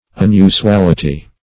Unusuality \Un*u`su*al"i*ty\, n.
unusuality.mp3